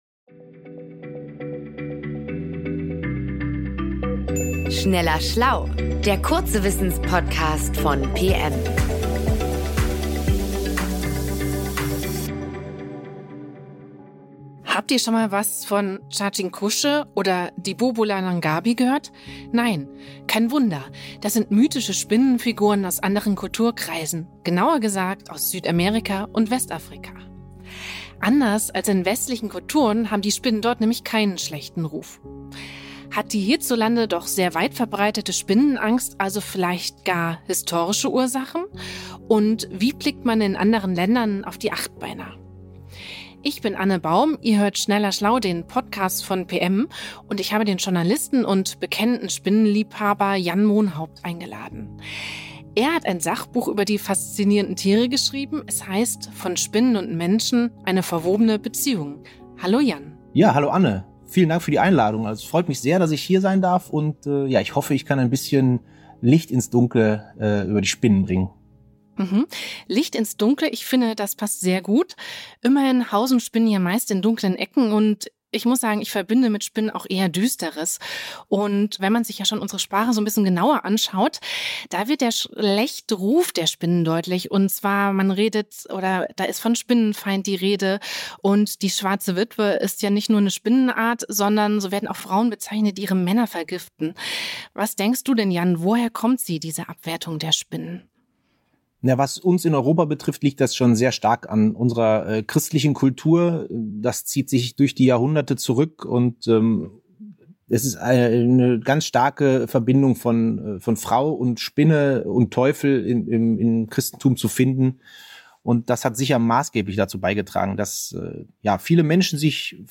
O-Ton Spinne